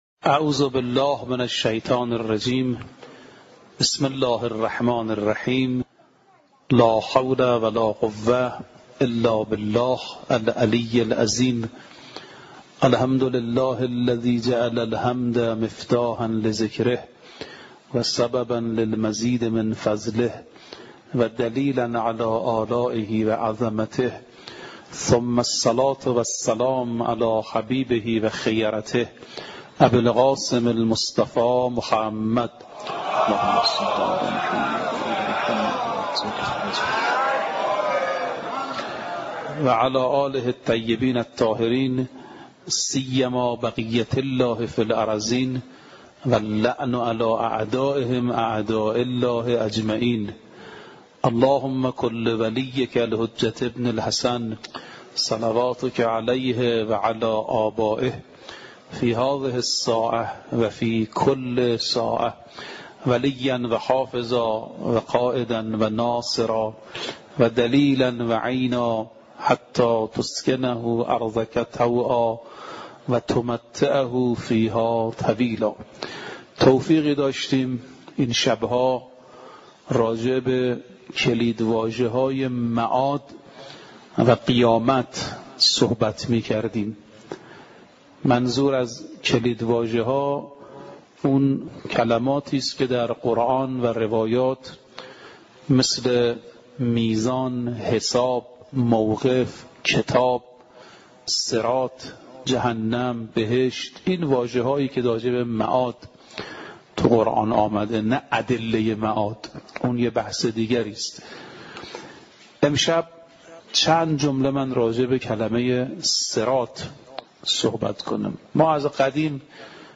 سخنرانی حجت الاسلام دکتر ناصر رفیعی با موضوع کلیدواژه های معاد در آیات و روایات